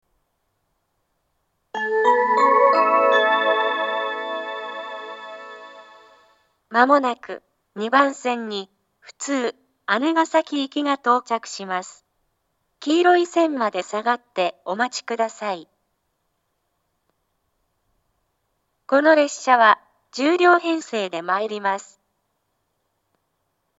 ２番線接近放送 普通姉ヶ崎行（１０両）の放送です。